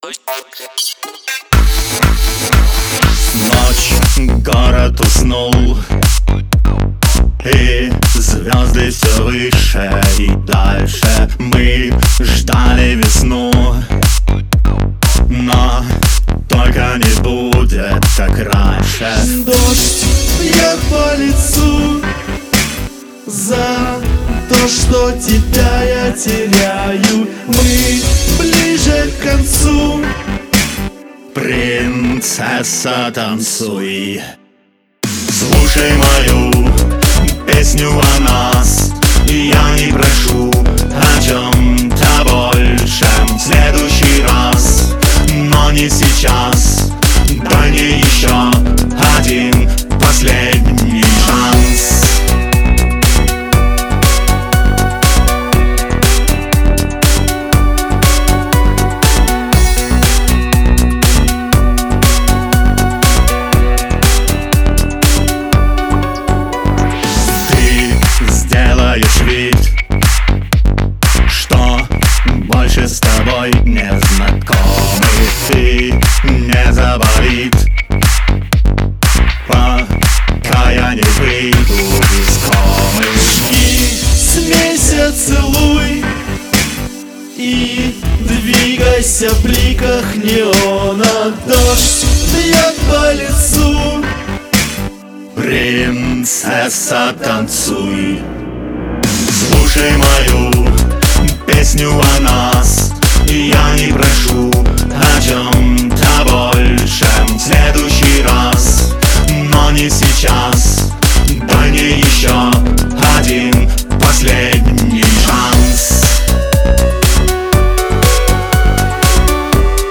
это трек в жанре синт-поп с элементами электронной музыки